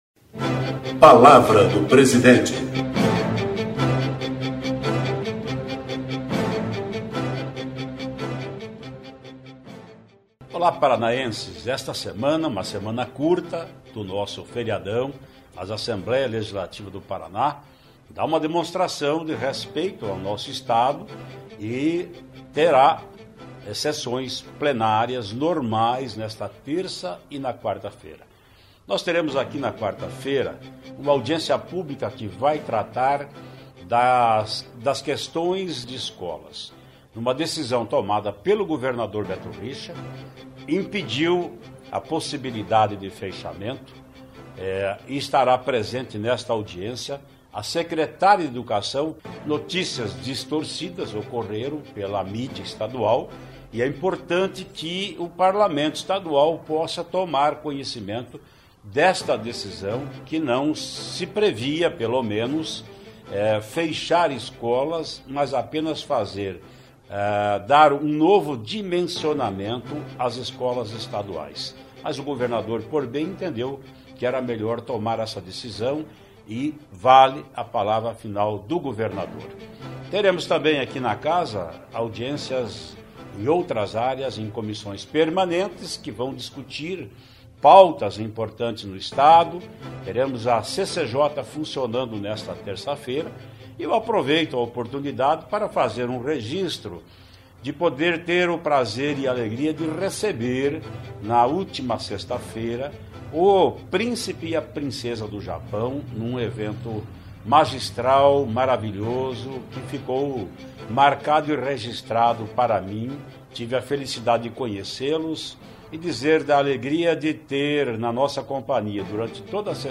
Ouça o que o presidente da Assembleia, deputado Ademar Traiano (PSDB), disse, na manhã desta terça (3) na Palavra do Presidente.
(Sonora)